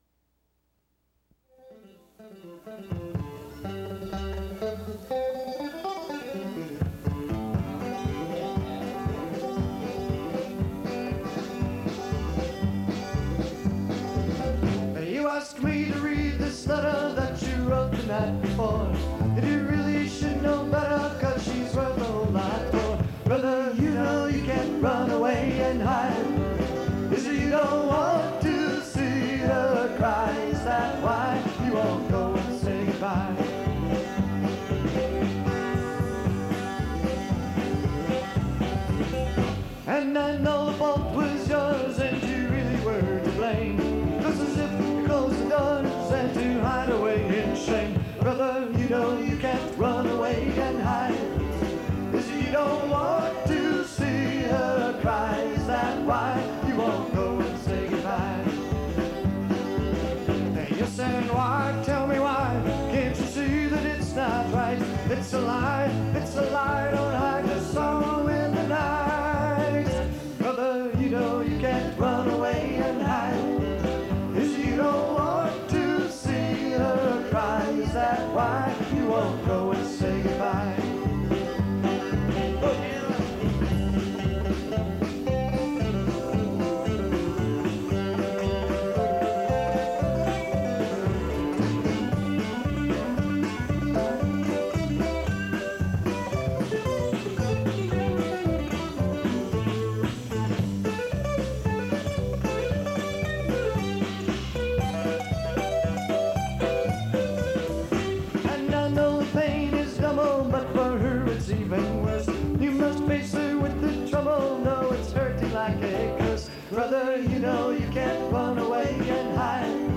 Category Folk/Rock
Studio/Live Live